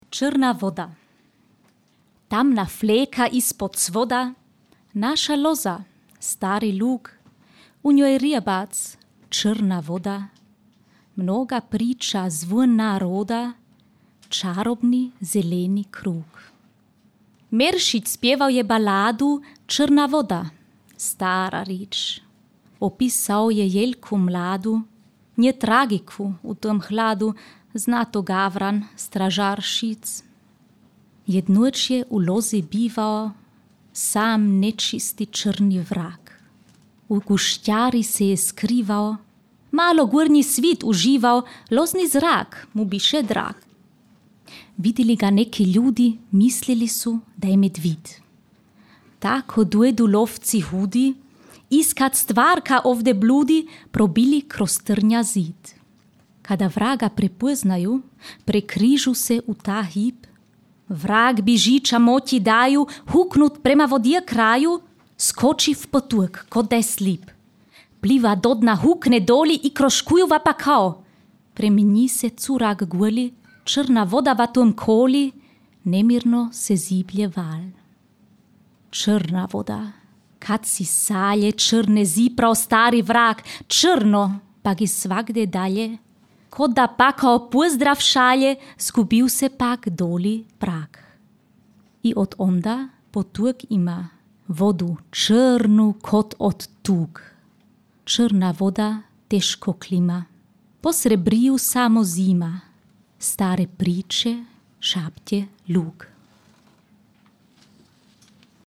čita: